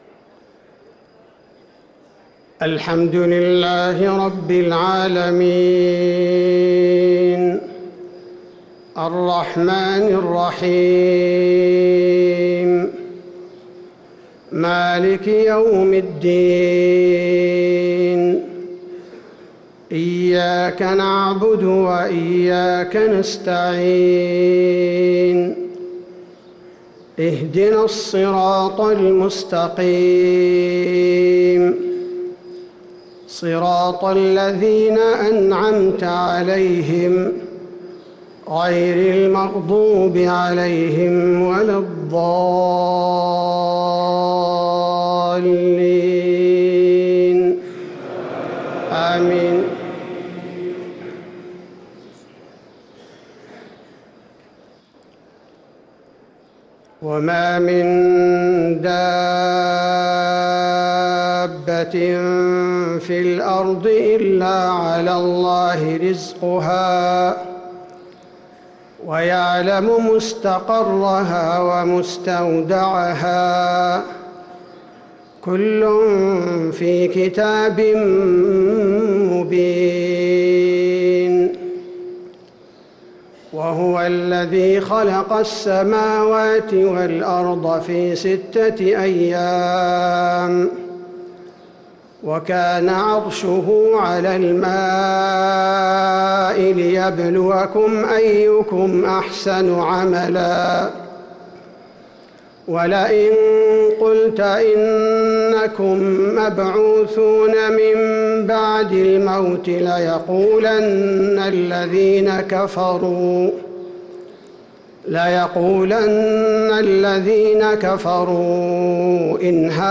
صلاة الفجر للقارئ عبدالباري الثبيتي 25 رمضان 1444 هـ
تِلَاوَات الْحَرَمَيْن .